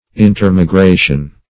Search Result for " intermigration" : The Collaborative International Dictionary of English v.0.48: Intermigration \In`ter*mi*gra"tion\, n. Reciprocal migration; interchange of dwelling place by migration.